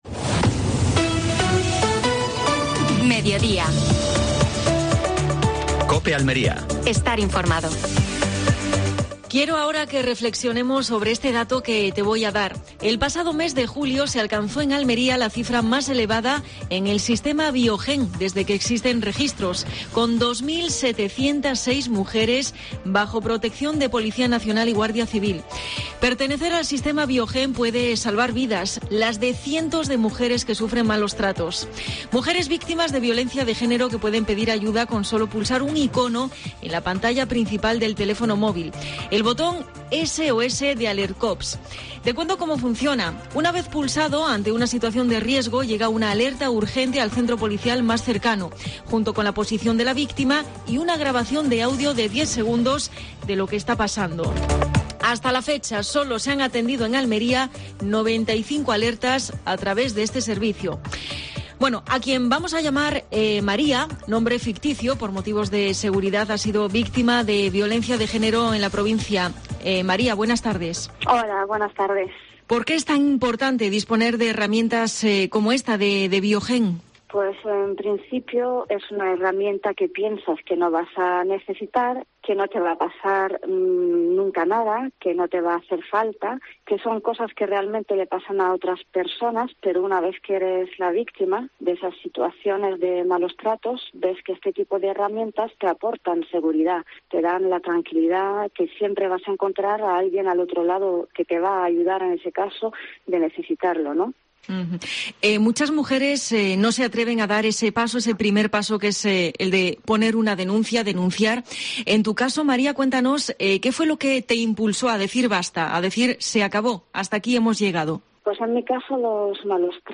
En Mediodía COPE Almería hablamos con una mujer que ha sufrido malos tratos y que destaca la importancia de herramientas como el Sistema VioGen para la protección de las víctimas.